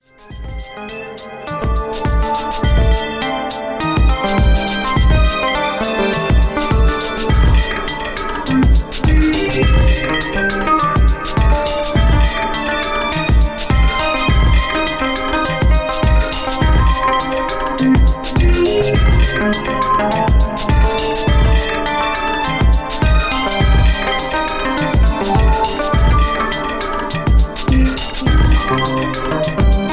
Royalty free smooth hip hop style background track.